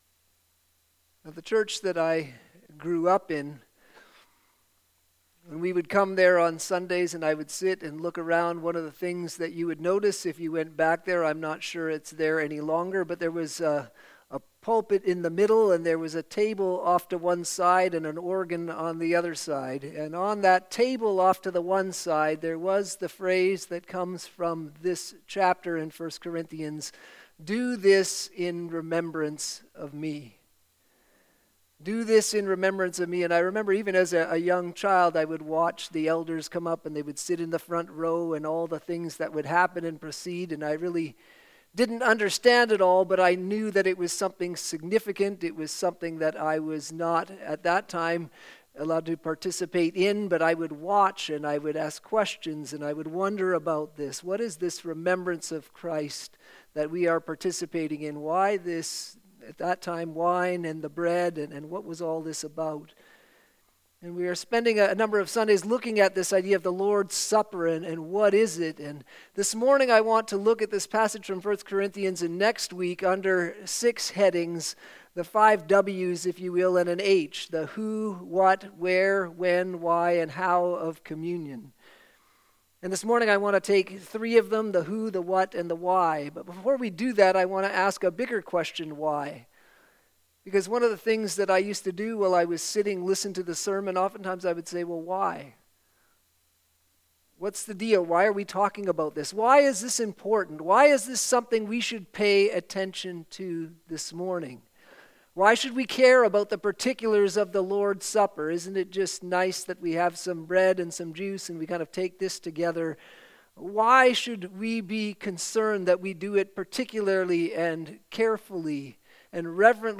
Sermons - Christ Community Church